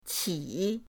qi3.mp3